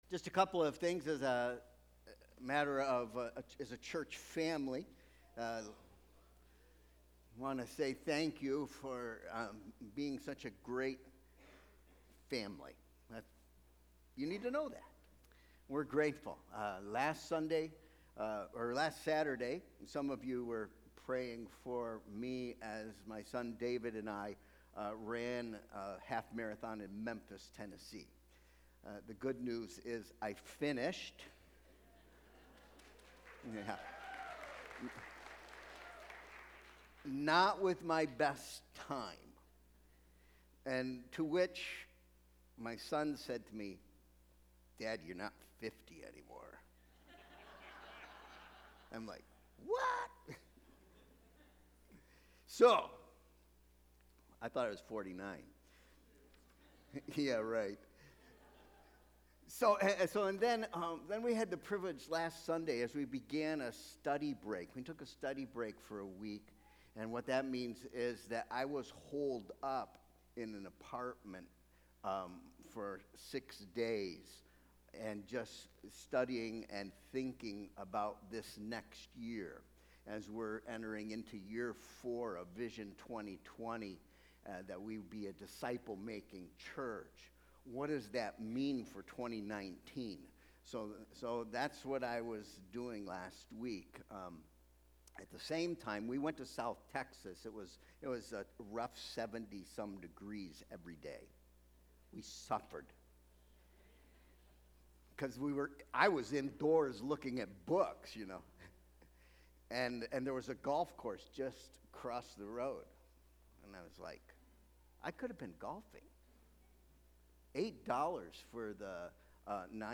Sermon Proposition: Like Mary, You Can Enjoy God’s Favor in 3 Ways.